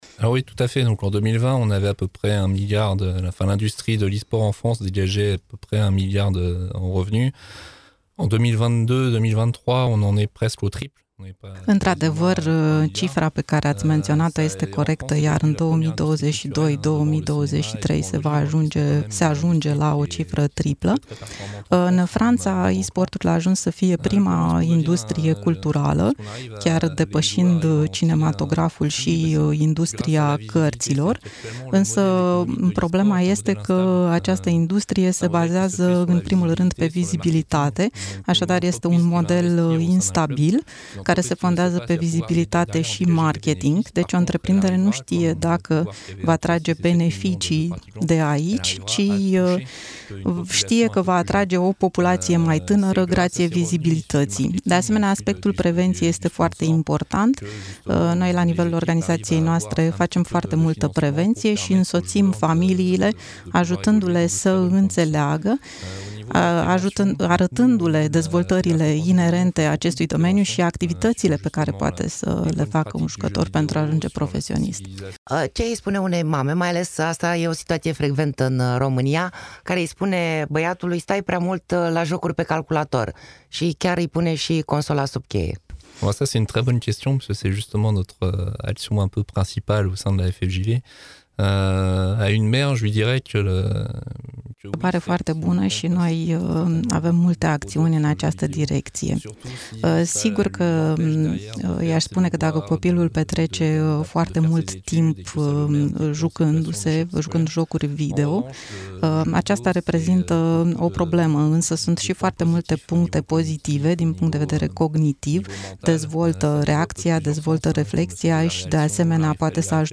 Interviu-esports.mp3